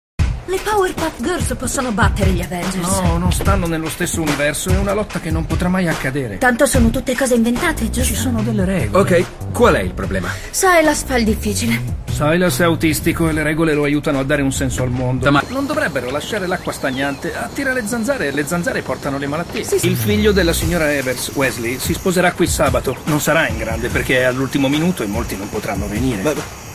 nel telefilm "The Rookie", in cui doppia Nik Sanchez.